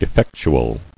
[ef·fec·tu·al]